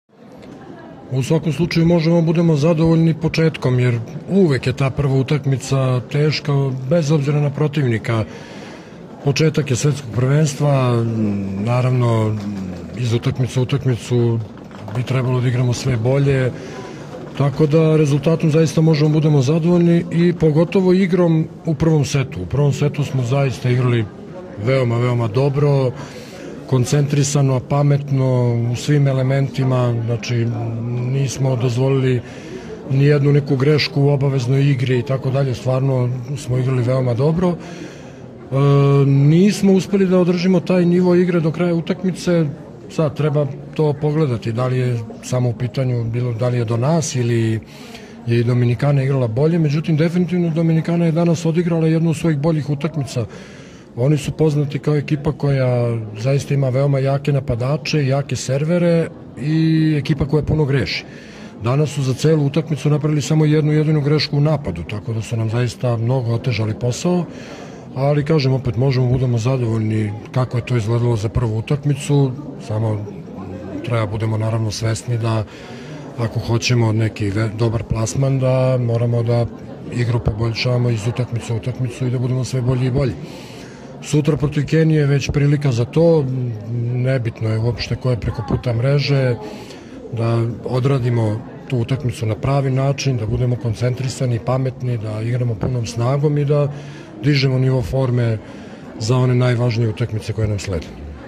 Izjava Zoran Terzić